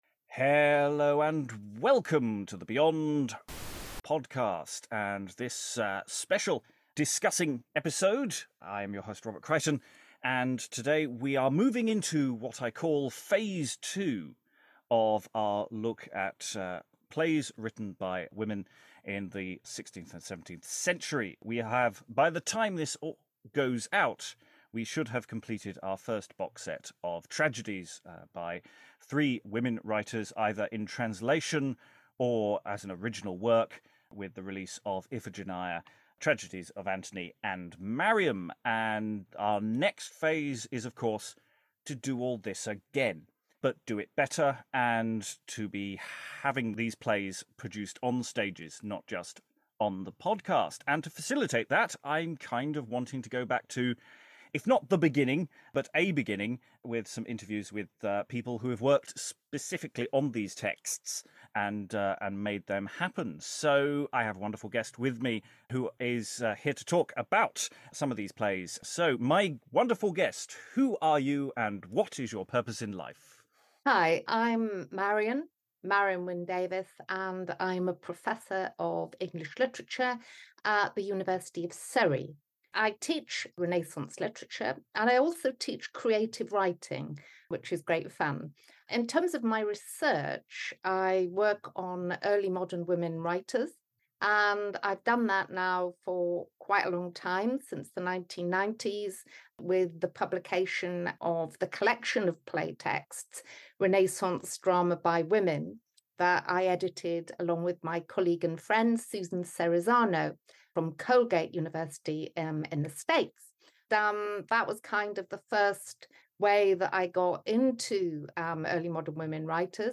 This episode features clips from our audio adaptations of the plays in question, all of which can be heard in full on our Dramatic Women playlist .